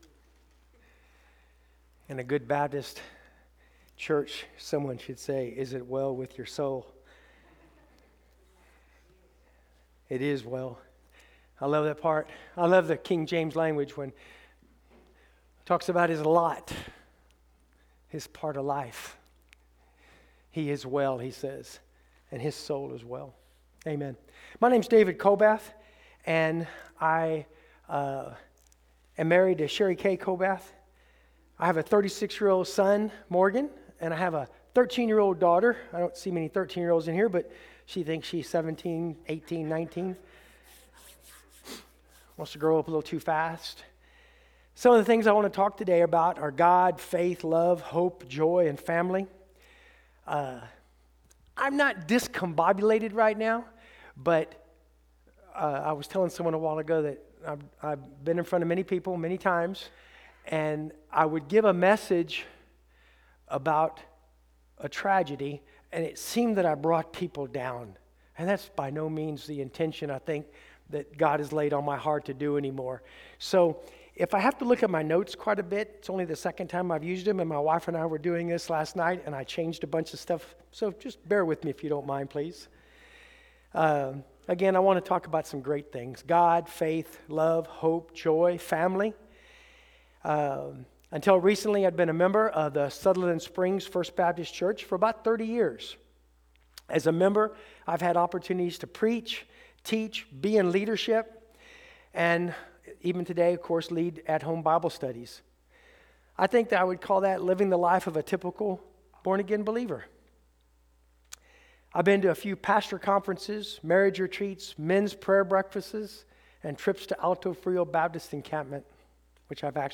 Sunday Sermon, January 21, 2024